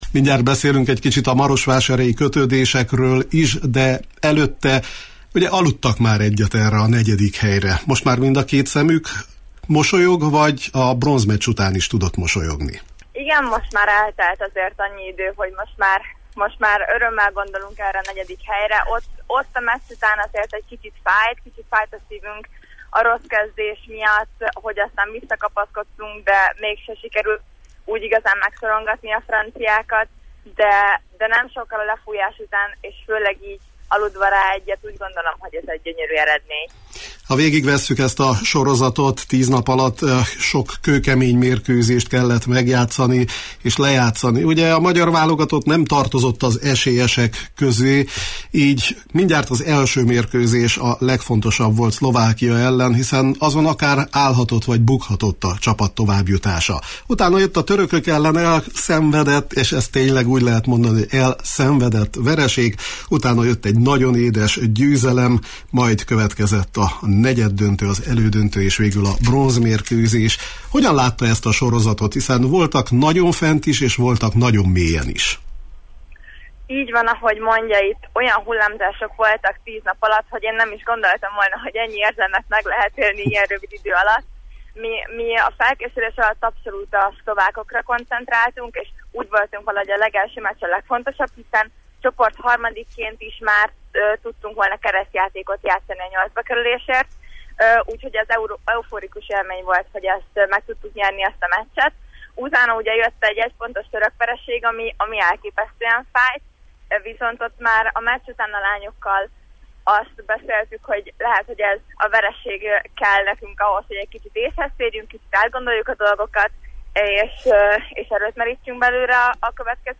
a Ljubljanából hazafele tartó autóbuszon érte utol telefonon